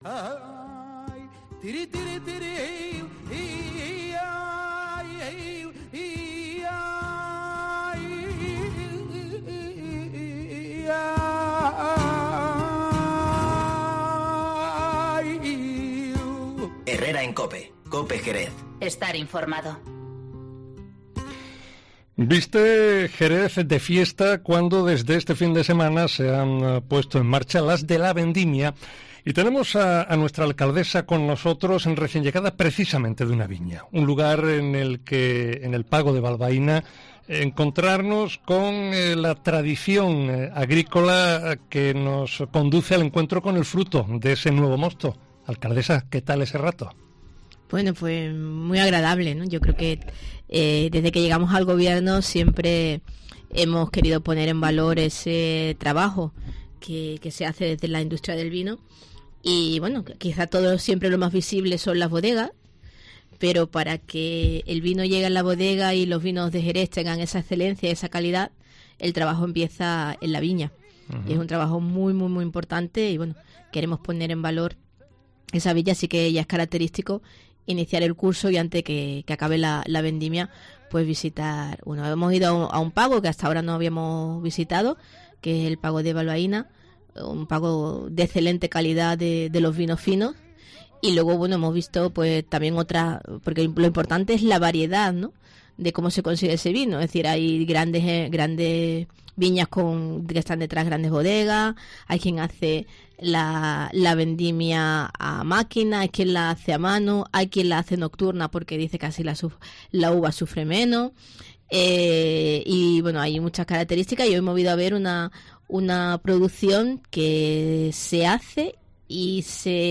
Mamen Sánchez, alcaldesa de Jerez, sobre las Fiestas de la Vendimia